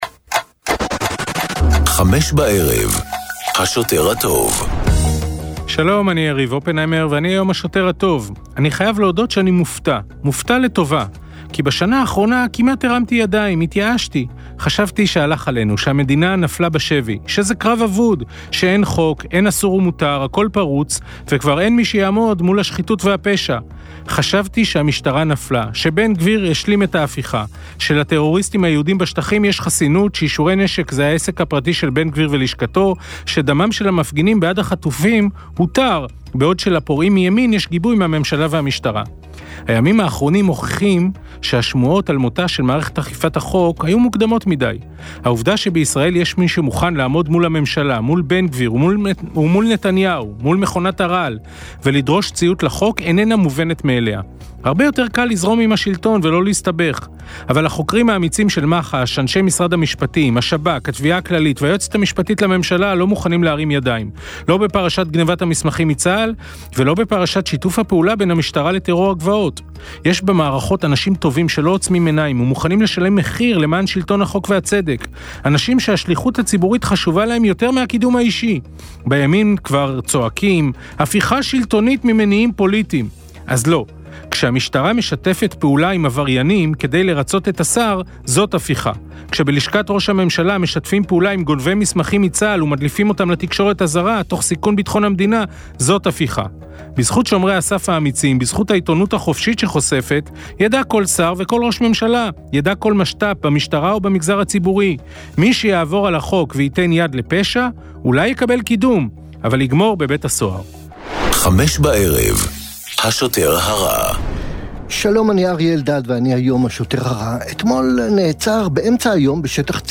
בן כספית, מהעיתונאים הבכירים והבולטים כיום במדינת ישראל, ופרופסור אריה אלדד, רופא, פובליציסט וכמובן חבר כנסת לשעבר מטעם האיחוד הלאומי ועוצמה לישראל, מגישים יחד תכנית אקטואליה חריפה וחדה המורכבת מריאיונות עם אישים בולטים והתעסקות בנושאים הבוערים שעל סדר היום.